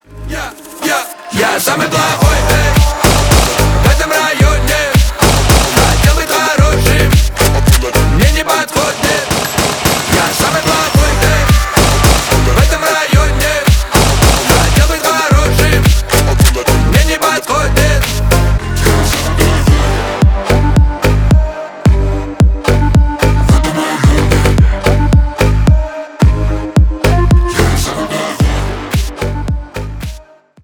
громкие
рэп , хип хоп